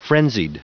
Prononciation du mot frenzied en anglais (fichier audio)
Prononciation du mot : frenzied